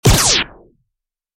zvuk-vystrela-lazera-laquopiuraquo
• Категория: Выстрелы «Пиу-пиу»